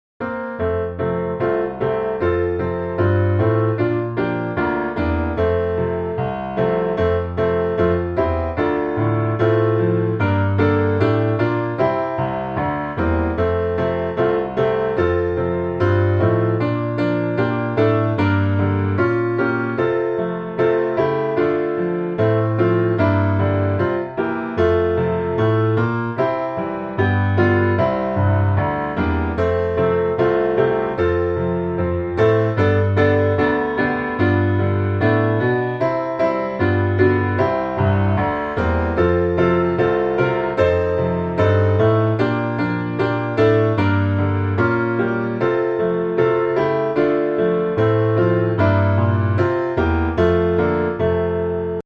Piano Hymns
F Major